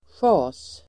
Ladda ner uttalet
sjas interjektion, be off!, shoo!Uttal: [sja:s] Variantform: även schasDefinition: försvinn!